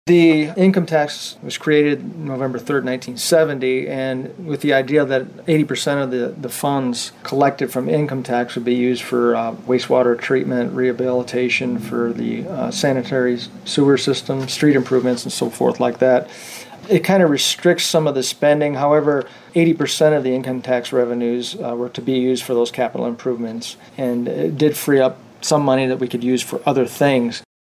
Interim City Manager Charles Weir talked to WLEN News about the history of the City income tax…